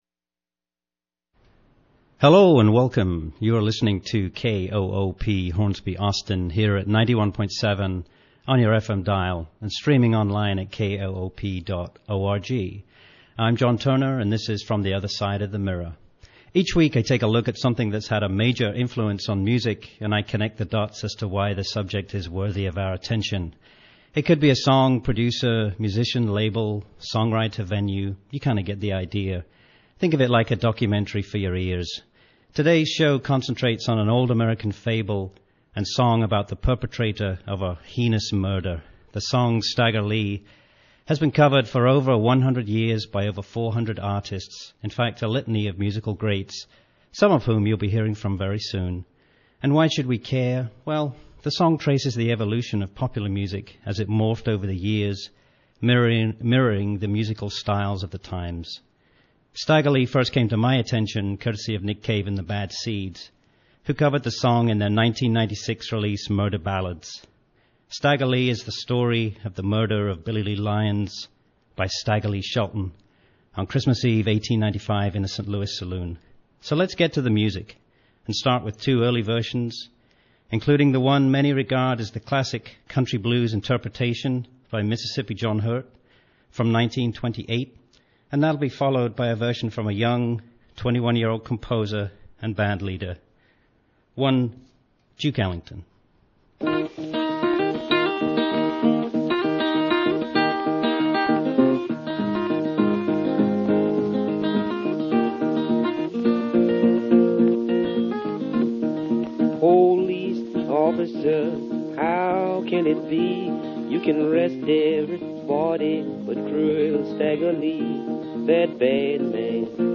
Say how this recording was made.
So please beg my indulgence, here is it, captured from the live stream.